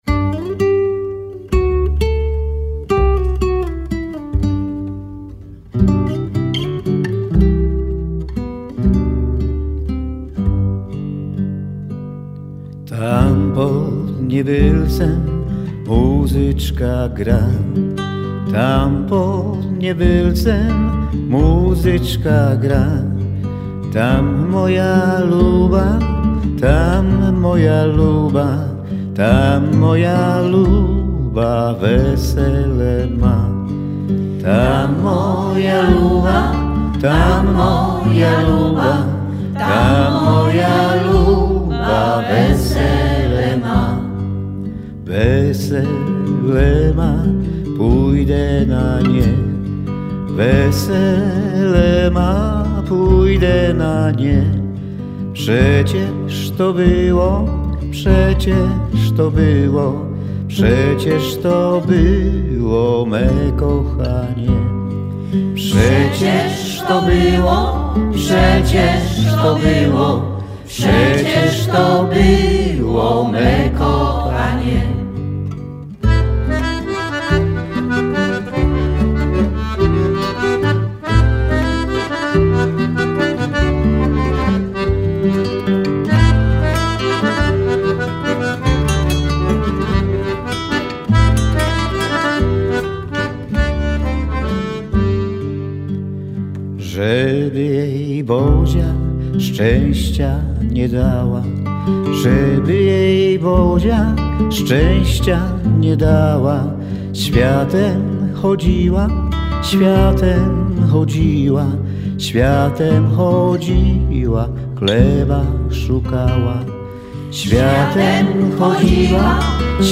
Nie zabraknie ciekawych historii, regionalnych melodii, zdjęć i rozmów przy poczęstunku. A wszystko w wykonaniu miejscowych seniorów, dzieci i młodzieży, którzy współpracowali z trenerkami z naszej Fundacji, reżyserem z międzynarodowym doświadczeniem teatralnym oraz grupą wolontariuszek